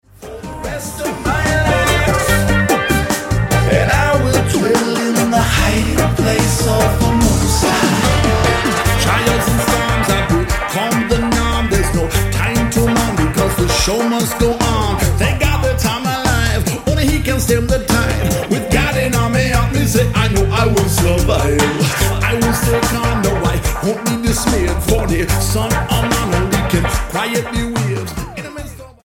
STYLE: Reggae